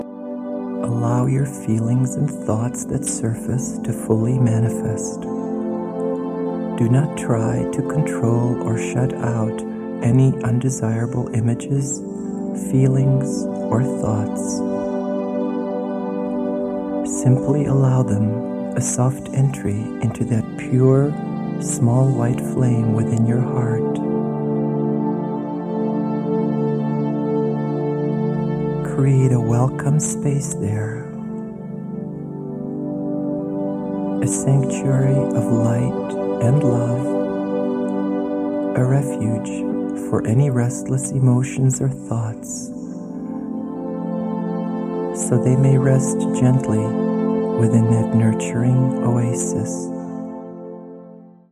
A dynamic digital download from a 2 CD set featuring 2 powerful meditations & visualization exercises designed to open and heal your heart, and to prepare you for your true love.